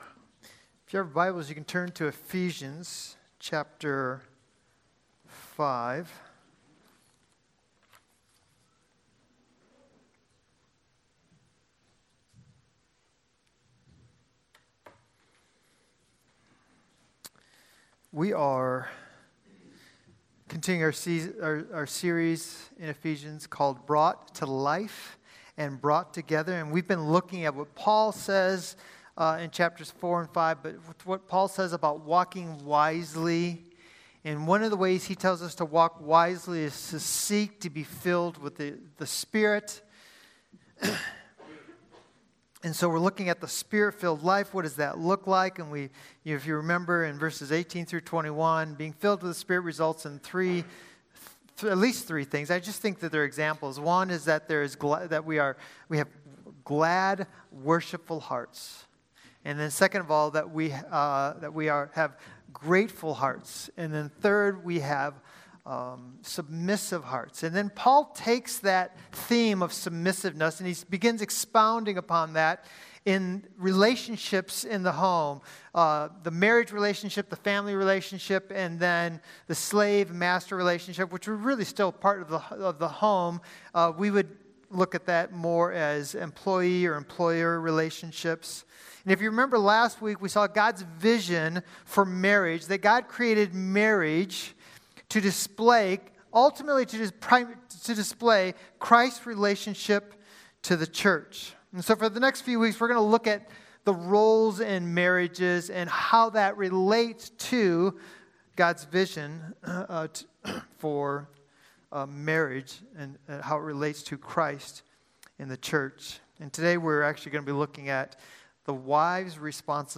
Sermons | Christian Community Church